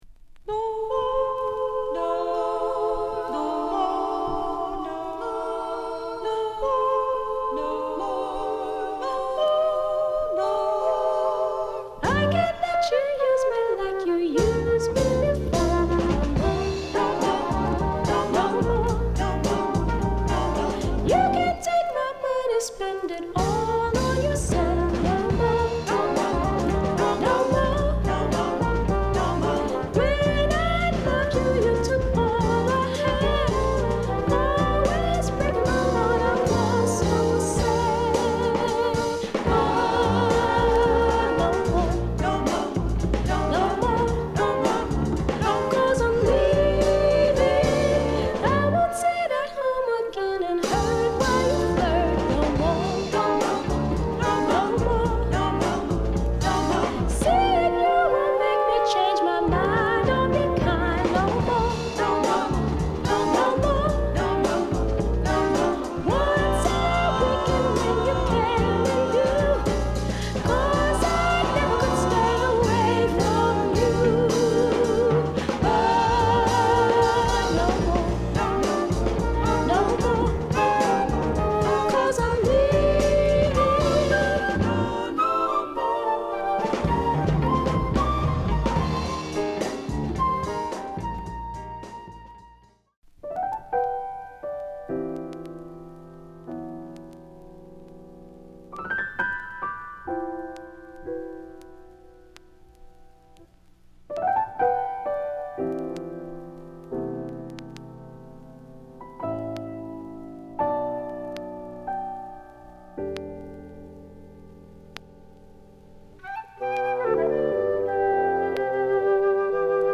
優美なソウル／ジャズを収録。